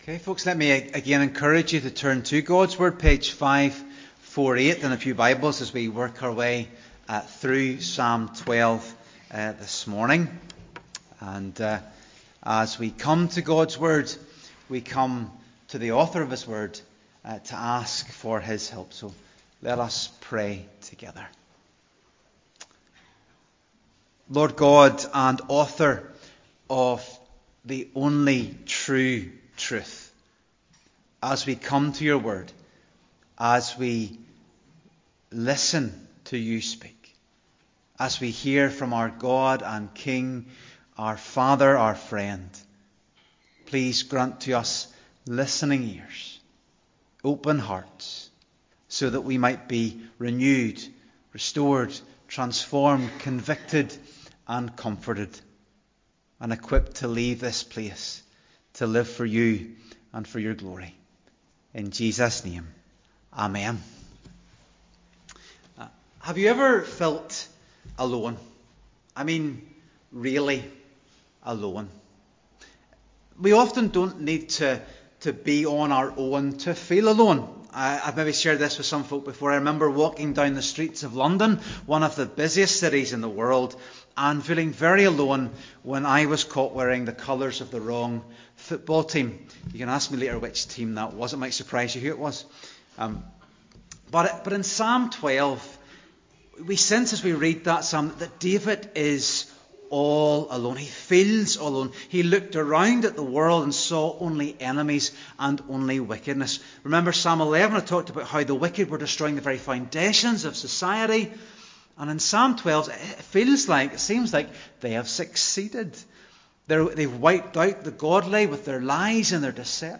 Psalm 12:1-8 Service Type: Sunday Morning Worship Psalm 12 The God of Truth Introduction Have you ever felt all alone?